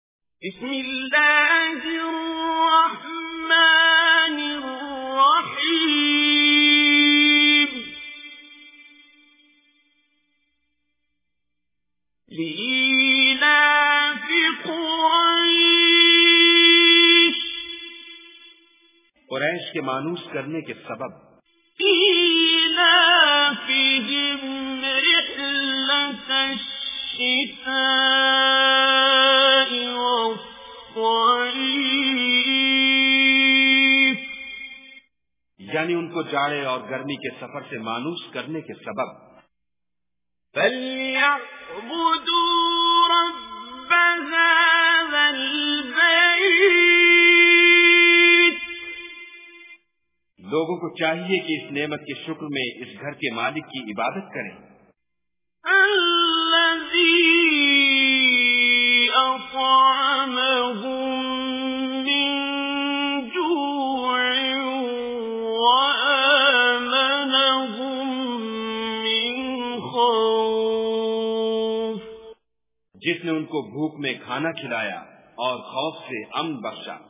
Surah Quraish Recitation with Urdu Translation
Surah Quraish is 106 chapter of Holy Quran. Listen online and download mp3 tilawat / recitation of Surah Quraish in the beautiful voice of Qari Abdul Basit As Samad.